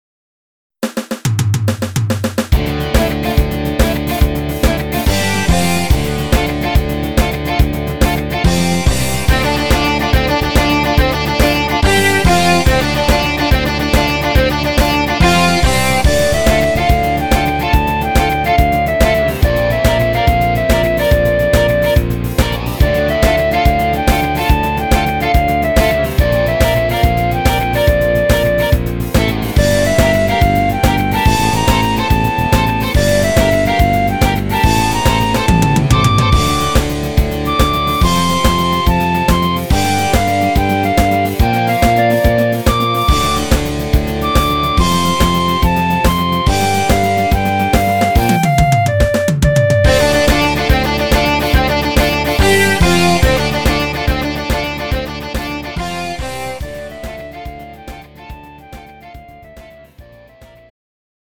음정 원키
장르 pop 구분 Lite MR